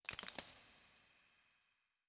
coin_begin.wav